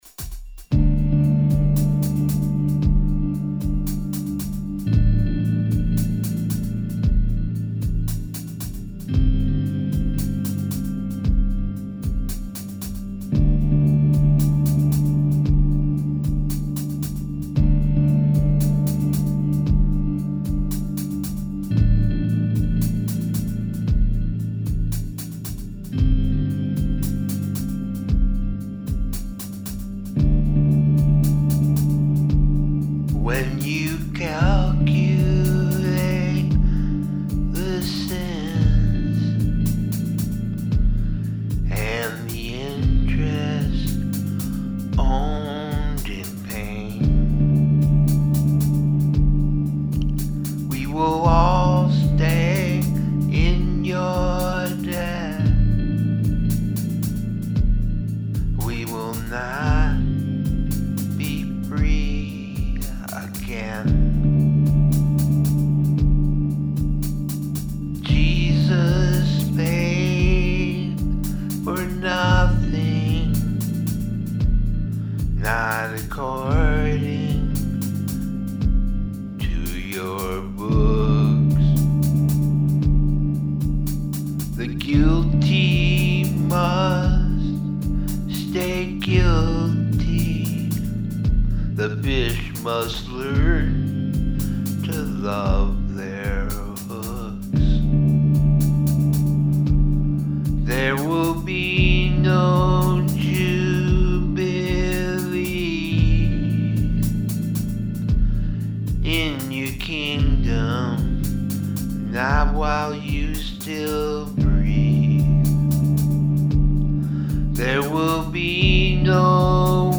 rock and/or roll
Good delivery.
Also, scary as shit and does exactly what you said on the tin.